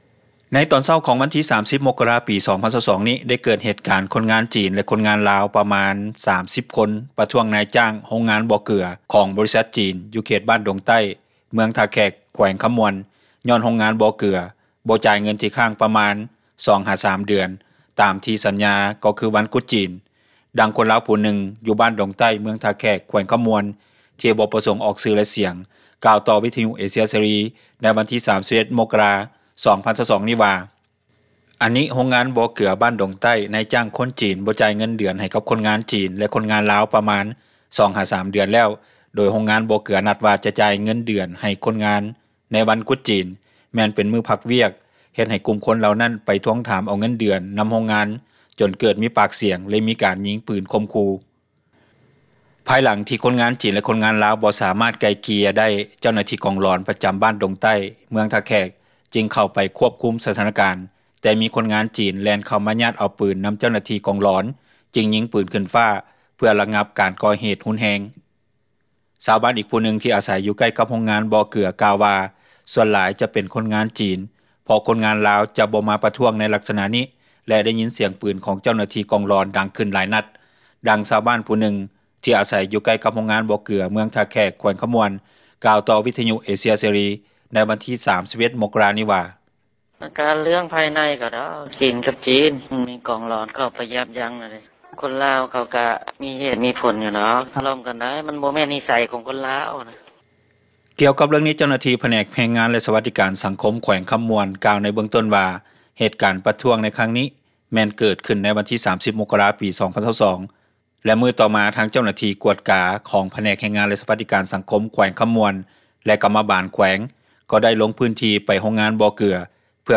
ດັ່ງຊາວບ້ານຜູ້ນຶ່ງ ທີ່ອາສັຍຢູ່ໃກ້ກັບໂຮງງານບໍ່ເກືອ ເມືອງທ່າແຂກ ແຂວງຄຳມ່ວນ ກ່າວຕໍ່ ວິທຍຸເອເຊັຽເສຣີ ໃນມື້ວັນທີ 31 ມົກກະຣານີ້ວ່າ:
ດັ່ງເຈົ້າໜ້າທີ່ ປກສ ແຂວງຄຳມ່ວນທ່ານນຶ່ງ ກ່າວຕໍ່ວິຍຸເອເຊັຽເສຣີໃນວັນທີ 31 ມົກກະຣານີ້ວ່າ: